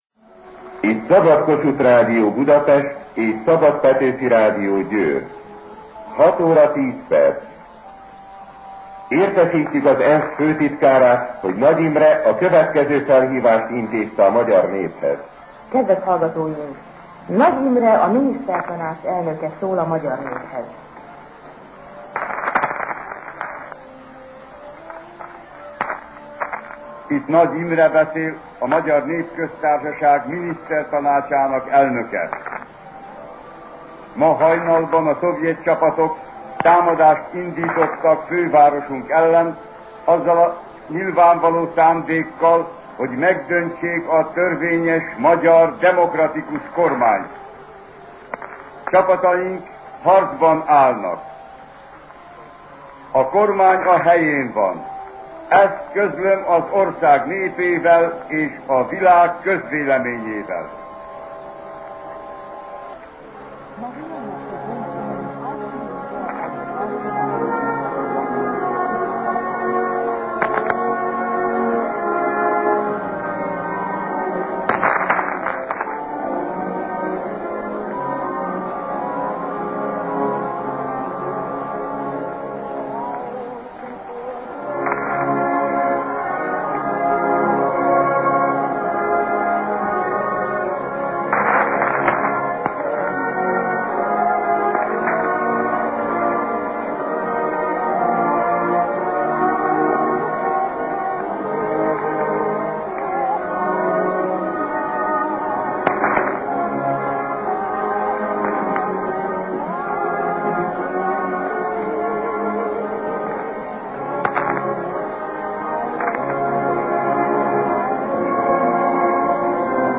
Fájl:1956-11-04 Nagy Imre felhívása az ENSZ főtitkárához (Szabad Kossuth Rádió).ogg – Hungaropédia